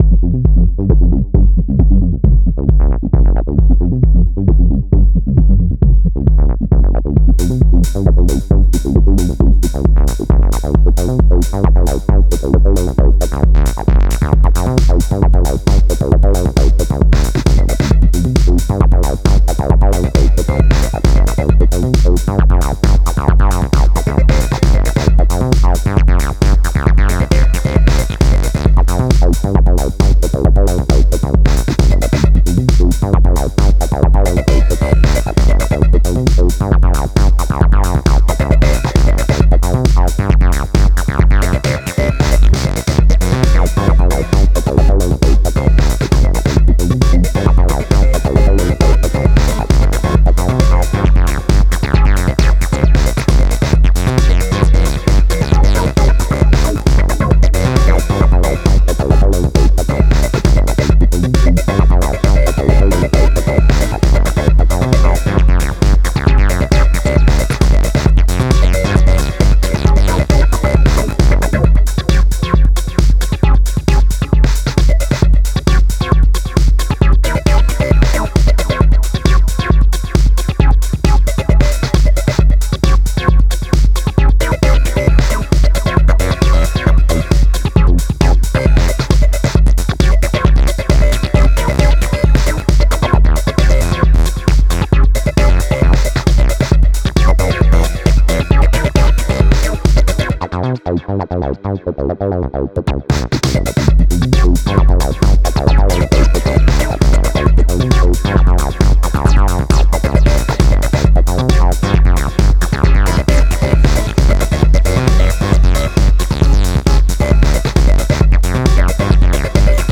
Music / Techno
acid techno idm microtonal